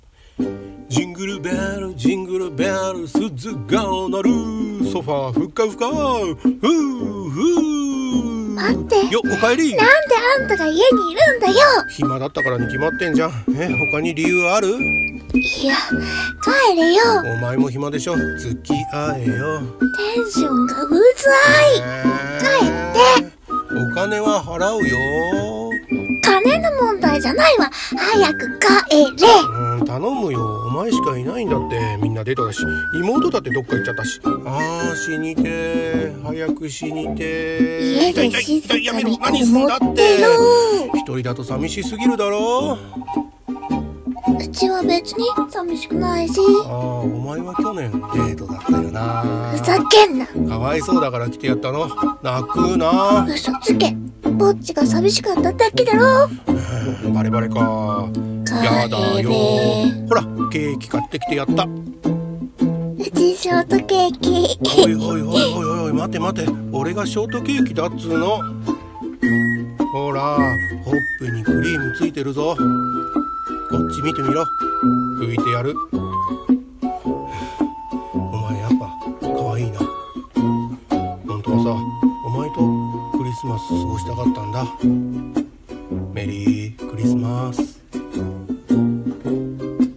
【二人声劇】 ぼっちクリスマス